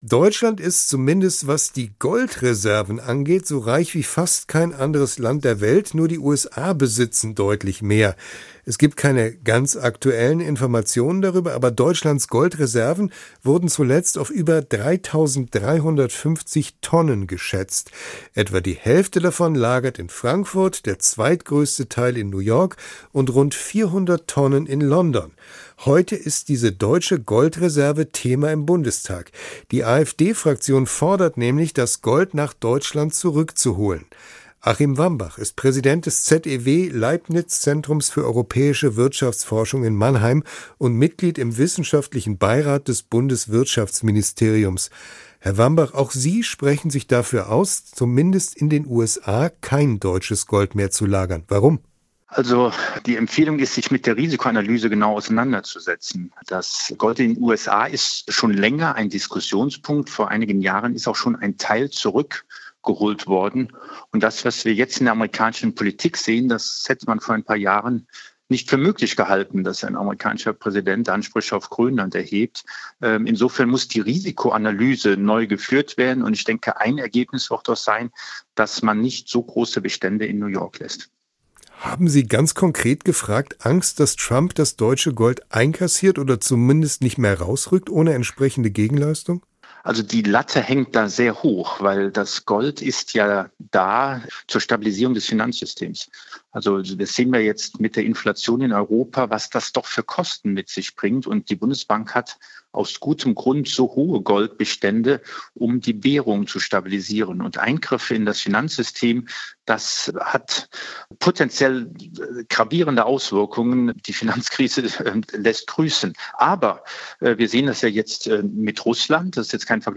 Das Interview führte